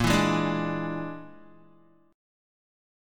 Bb7b5 chord